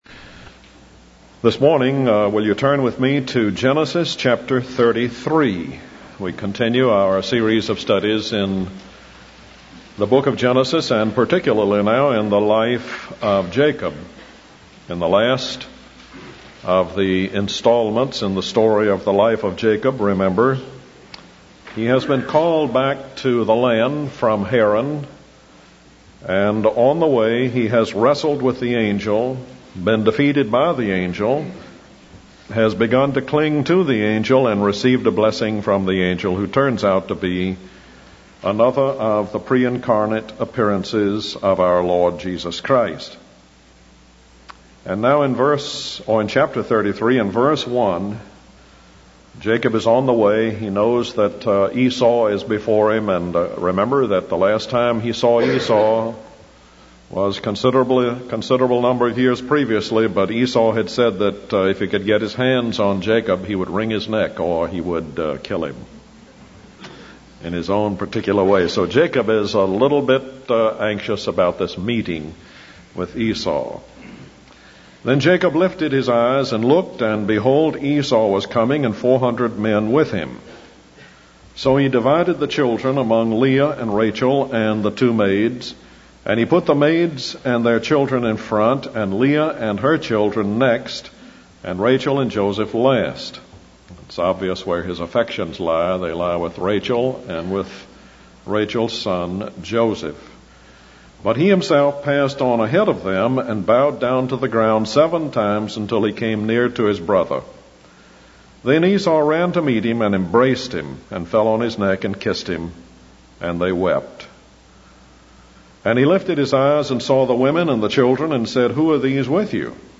In this sermon, the preacher focuses on the story of Jacob and Esau in Genesis chapter 33. He highlights the danger of degeneration in the Christian life and emphasizes the importance of staying faithful to God. The preacher also discusses the tests of life that Jacob faced, including his meeting with the angel and his eventual reunion with Esau.